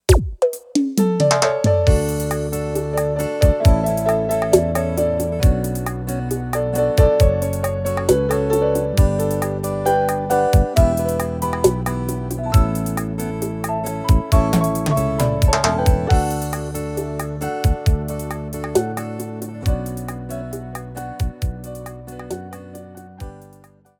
• Demonstrativo Seresta: